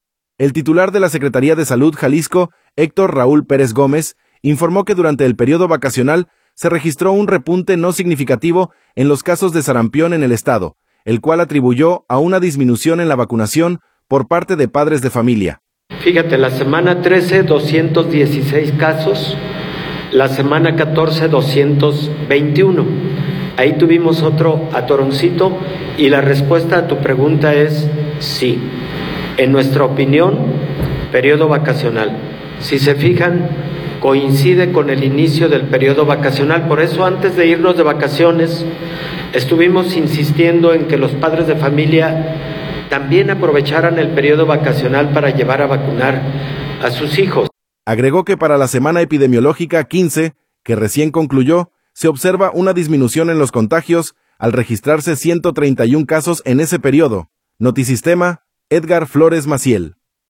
El titular de la Secretaría de Salud Jalisco, Héctor Raúl Pérez Gómez, informó que durante el periodo vacacional se registró un repunte no significativo en los casos de sarampión en el estado, el cual atribuyó a una disminución en la vacunación por parte de padres de familia.